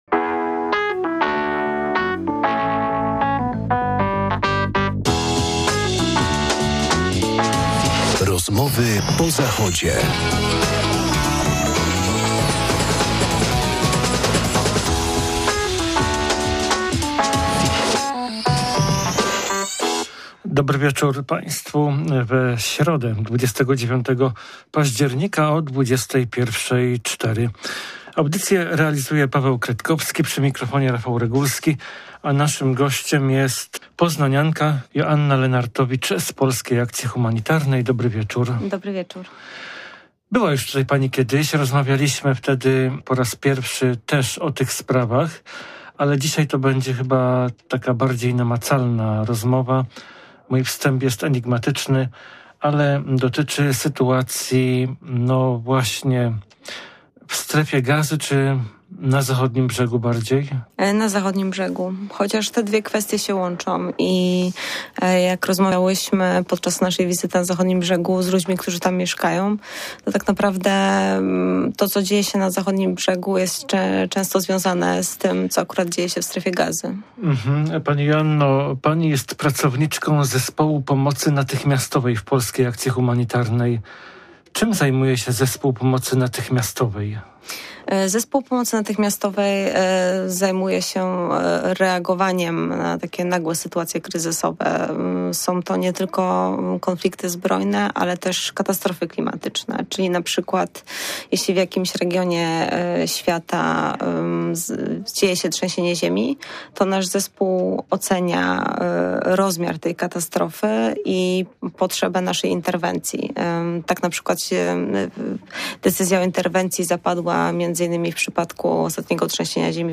Jak działają tam mobilne kliniki wspierane przez PAH, jak wygląda przemieszczanie się tej służby zdrowia na kółkach pomiędzy strefami, pokonywanie check-pointów i poruszanie się w labiryncie i gąszczu barier, zasieków i przeszkód - o tym i kilku innych sprawach usłyszycie w tej rozmowie. A do tego - muzyka z Palestyny!